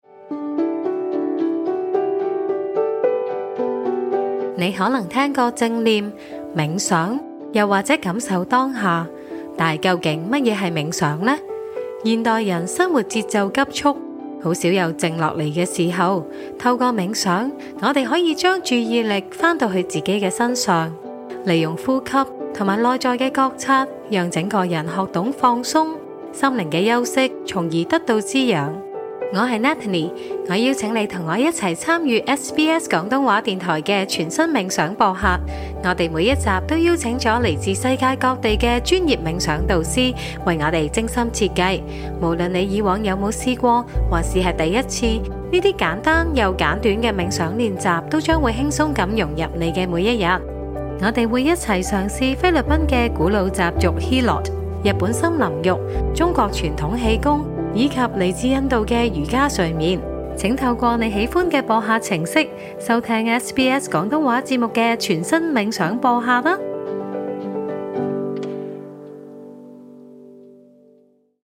Great Minds, a meditation podcast by SBS, is now available in Cantonese